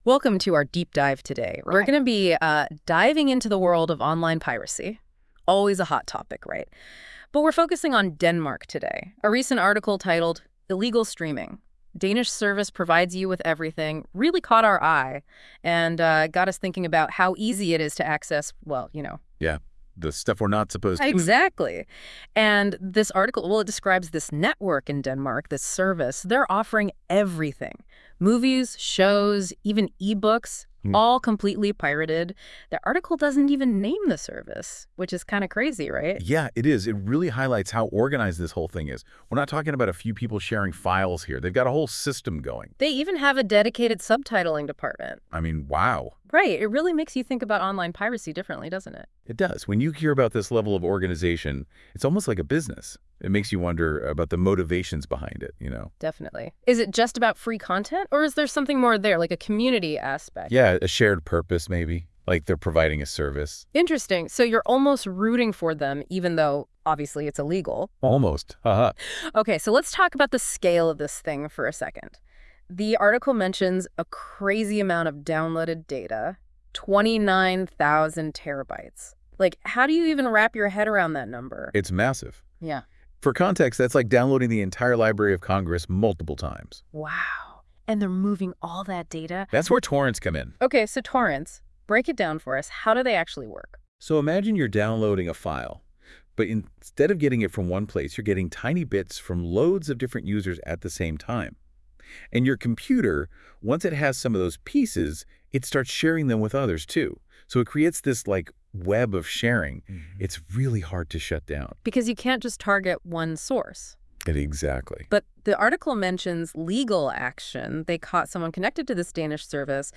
Den genererede podcast har samme dynamik som en ‘rigtig’ podcast, og der er et naturligt samspil mellem værterne, som får det til at føles ægte.
Hør vores AI-genererede podcast om ulovlig streaming
NotebookLM blev fodret med cirka 1.000 ord og producerede en podcast på omkring fire minutter.
AI-podcastdemo.wav